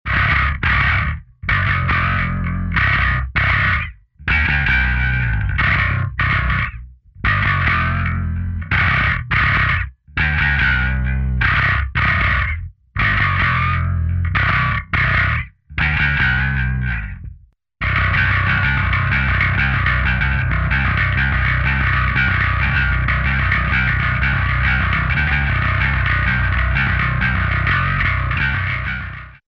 Das klingt doch schon sehr nach den Darkglass-Sachen à la Obsidian.
Dann schön clangy oben rum. ich habe noch zu viel Drive drin.